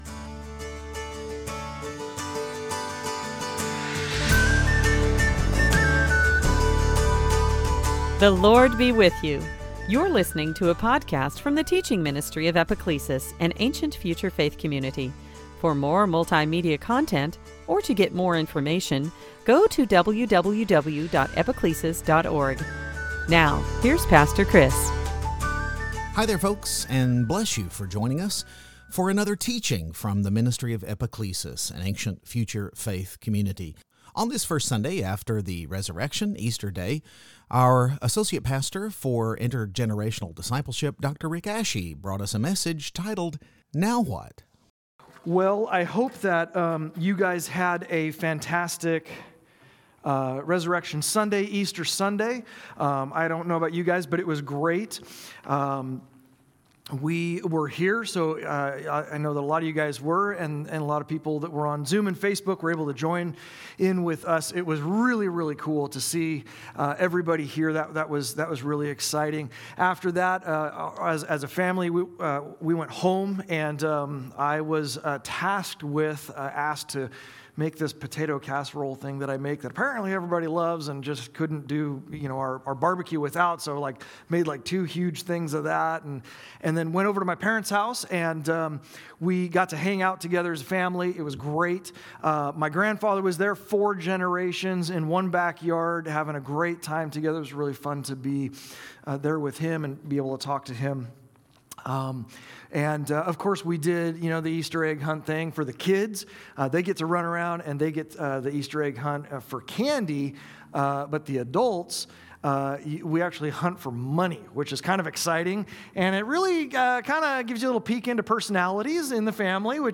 2021 Sunday Teaching Gospel of John John 20 resurrection Thomas Upper Room